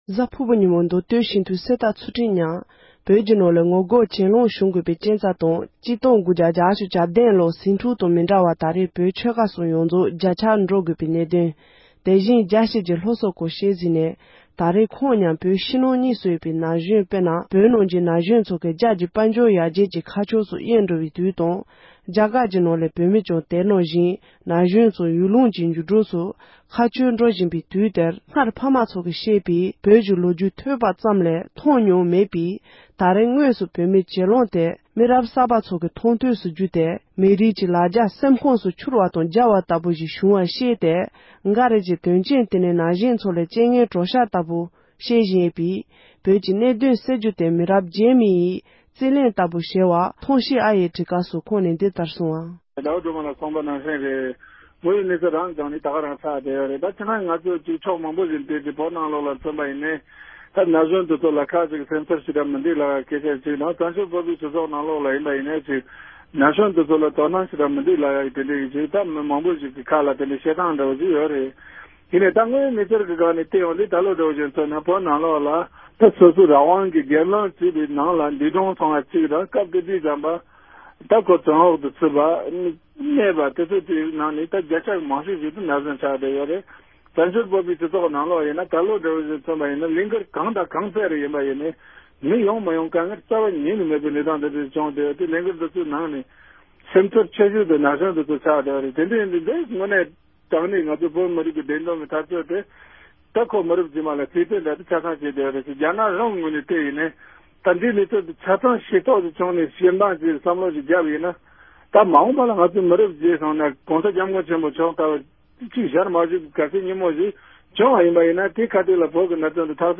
བོད་མི་དམངས་མདོ་སྟོད་སྤྱི་འཐུས་གསེར་རྟ་ཚུལ་ཁྲིམས་མཉམ་གླེང་བ།